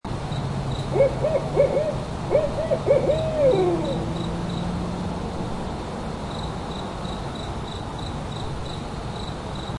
Crickets and Owl: Instant Play Sound Effect Button